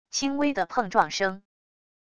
轻微的碰撞声wav音频